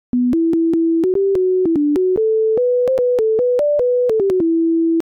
Hair thickness is proportional to the number of different patterns in a tune, and these tunes have, relatively speaking, a small number of different patterns, largely because they lack tied notes and large intervals, features common to many folk tunes.